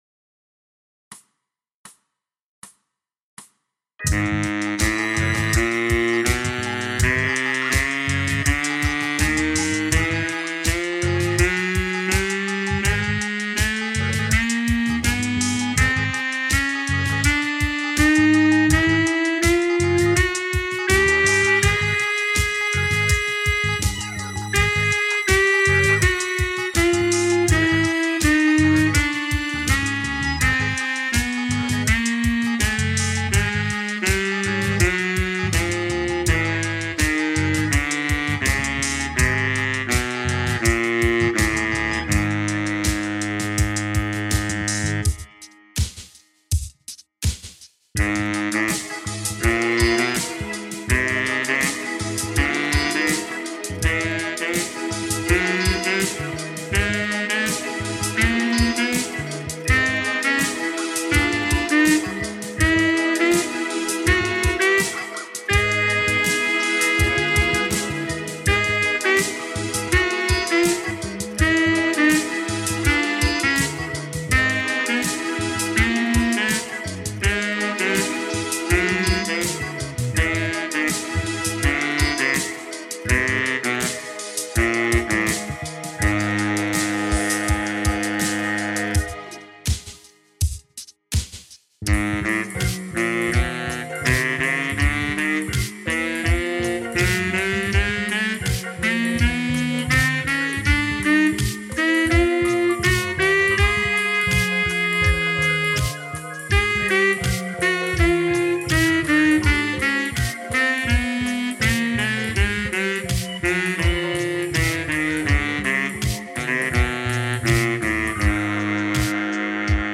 for Tenor Sax